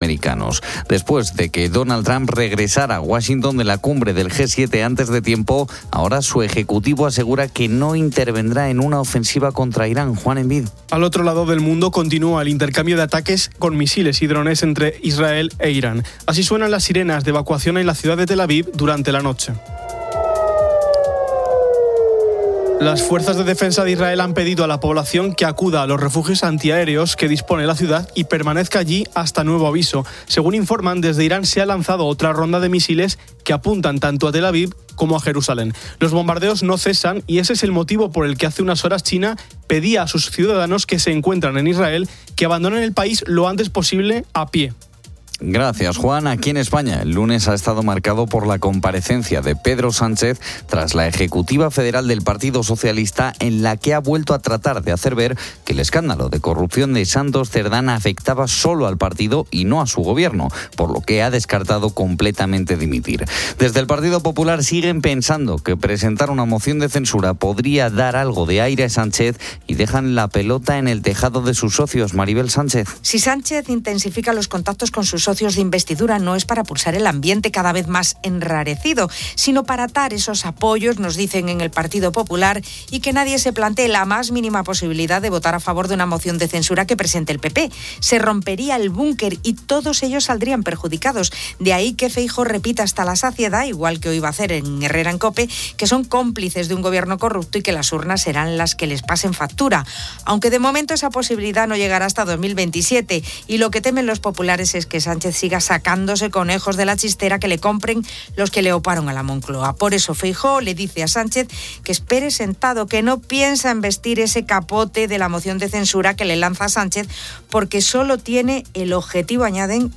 Al otro lado del mundo continúa el intercambio de ataques con misiles y drones entre Israel e Irán. Así suenan las sirenas de evacuación en la ciudad de Tel Aviv durante la noche.